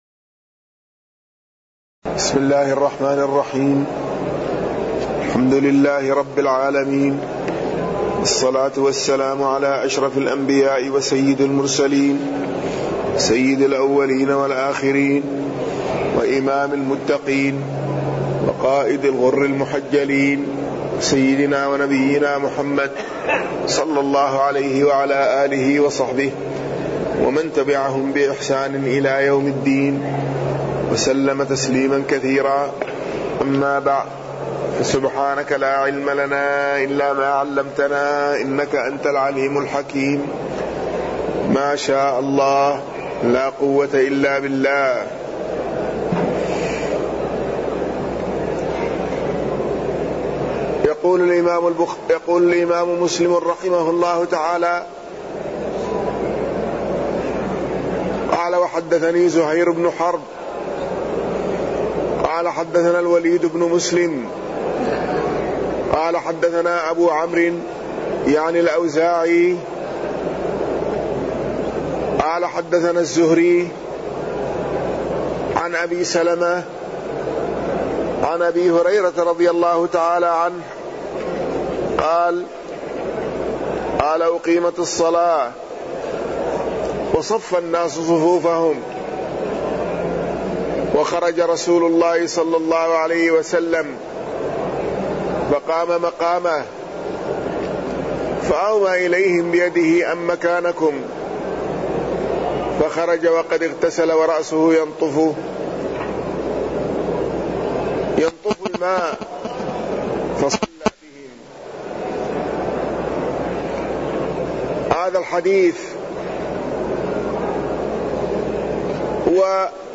تاريخ النشر ١٣ شوال ١٤٢٩ هـ المكان: المسجد النبوي الشيخ